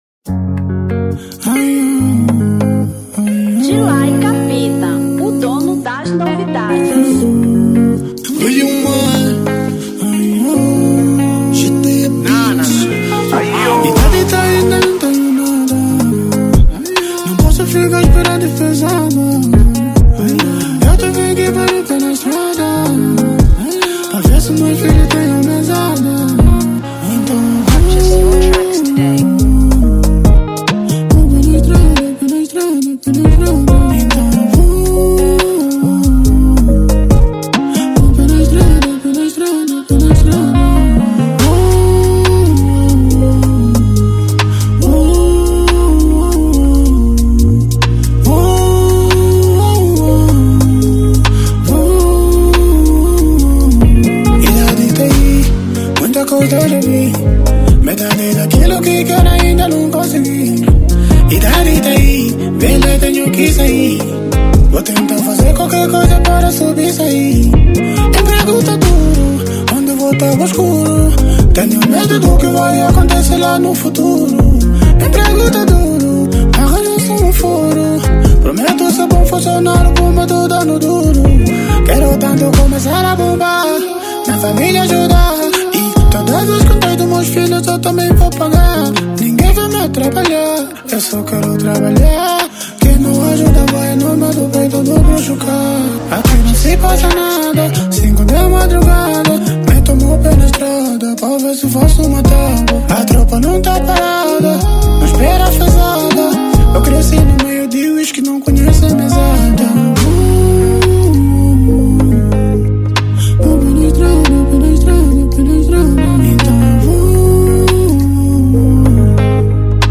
Drill 2023